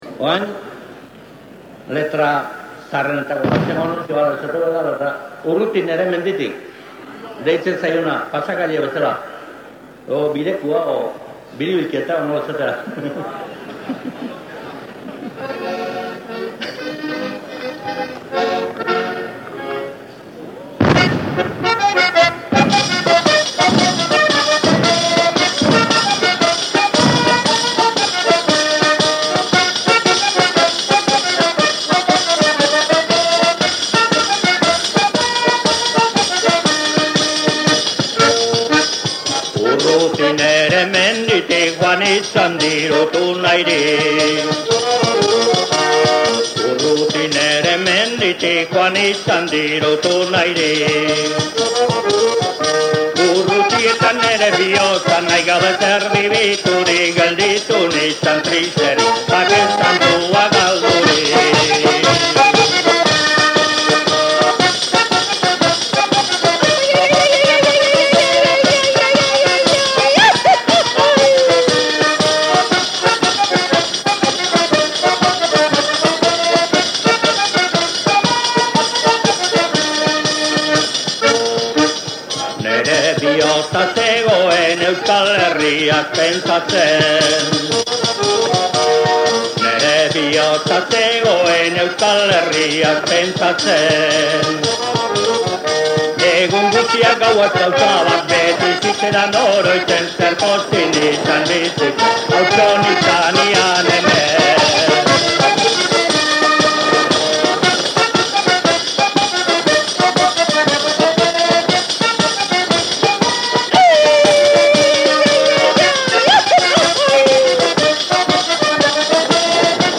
Urruti nere menditik (Biribilketa).